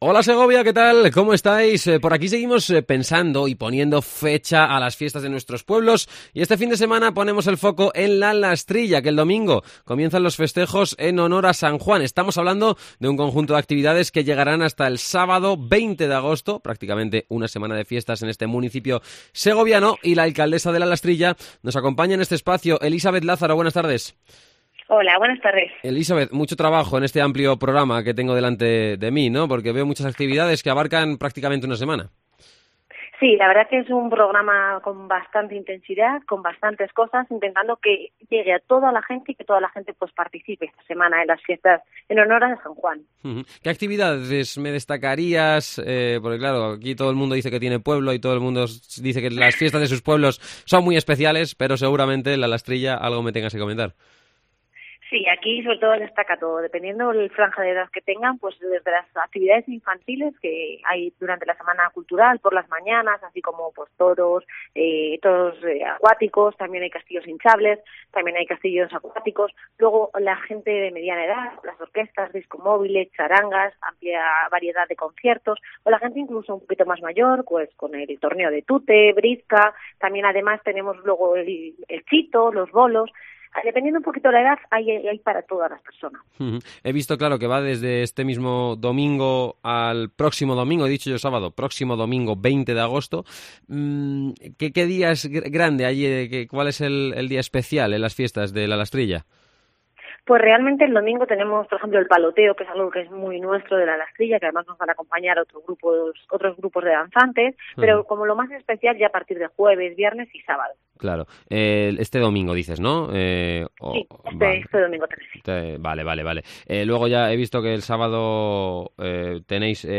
Entrevista a Elisabeth Lázaro, Alcaldesa de La Lastrilla